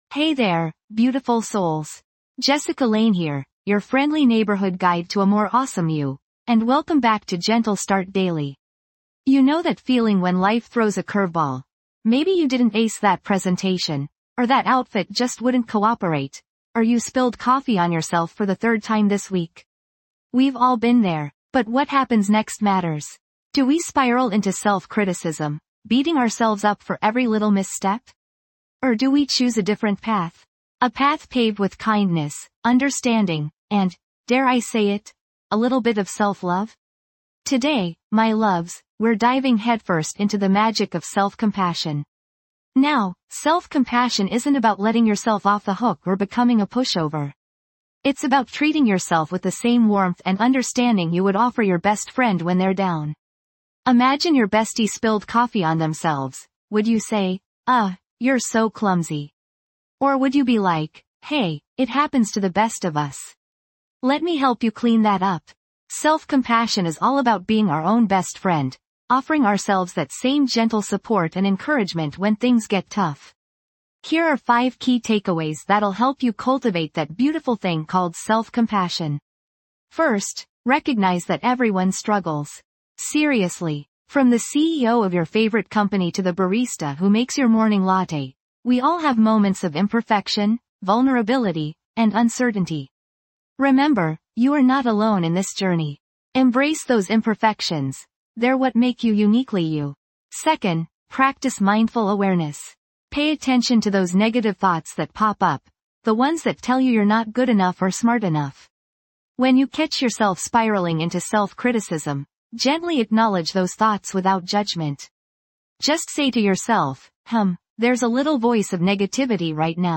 Each episode offers a soothing blend of affirmations, guided meditations, and calming soundscapes designed to ease you into your day with tranquility and clarity.